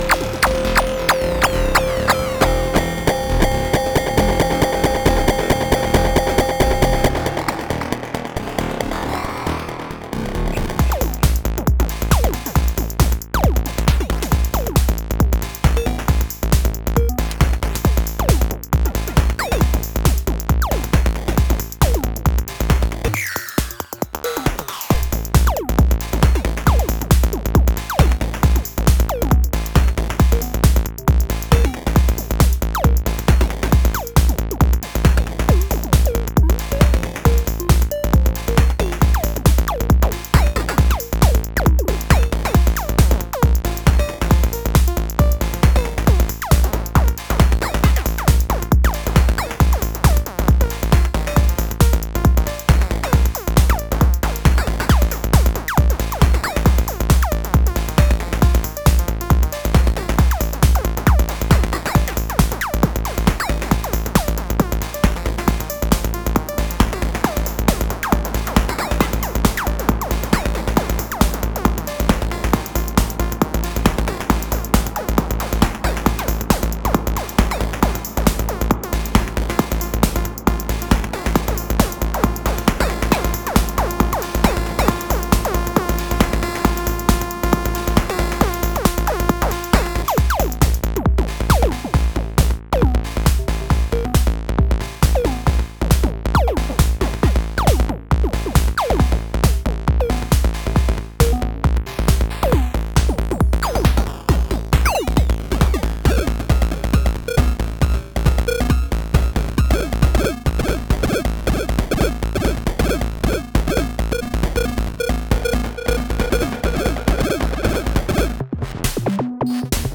Erica Synths LXR-02 Desktop Digital Drum Synthesizer
LXR-02 only in this one. Just 3 patterns, but I’ve used 5 of LFO’s on just one track, cos why not ?!?